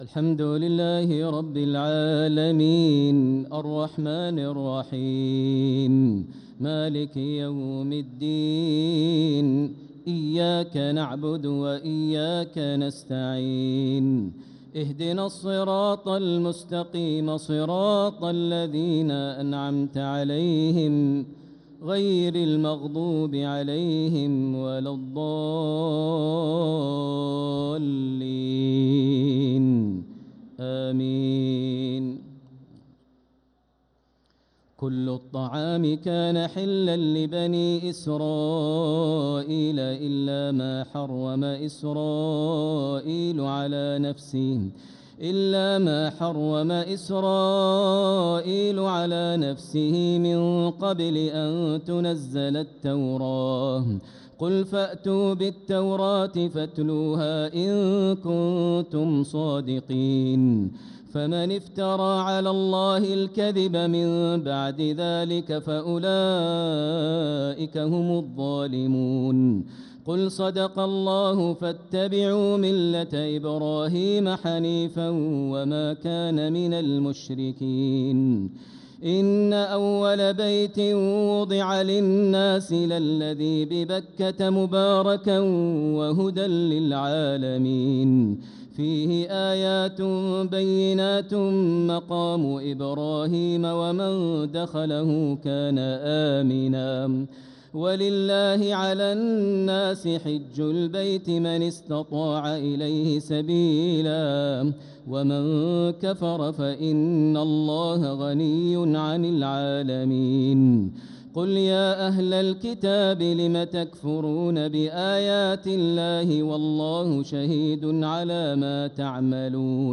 تراويح ليلة 5 رمضان 1446هـ من سورة آل عمران (93-158) | Taraweeh 5th night Ramadan 1446H Surat Aal-i-Imraan > تراويح الحرم المكي عام 1446 🕋 > التراويح - تلاوات الحرمين
Taraweehmakkah.mp3